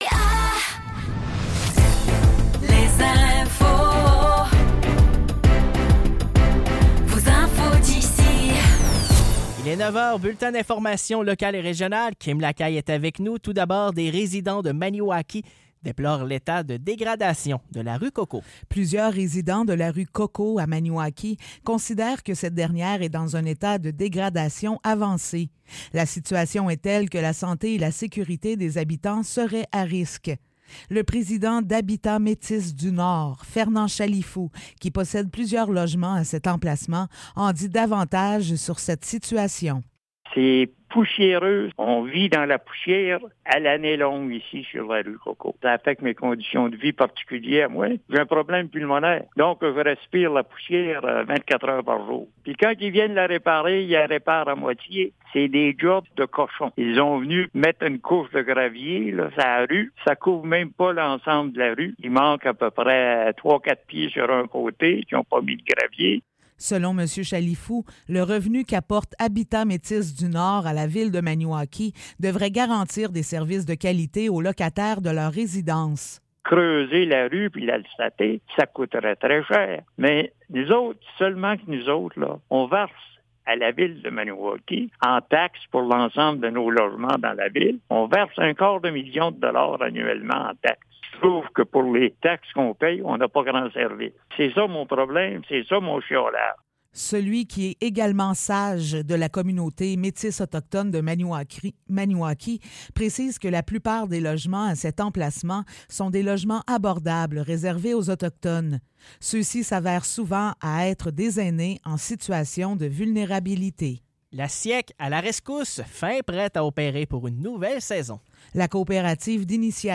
Nouvelles locales - 11 juillet 2024 - 9 h